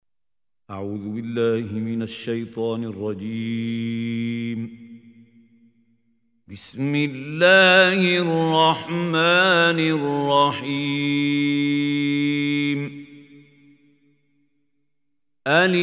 Surah Ar Raad Online Recitation by Khalil Hussary
Listen online and download beautiful Quran tilawat / recitation of Surah Ar Raad in the beautiful voice of Mahmoud Khalil Al Hussary.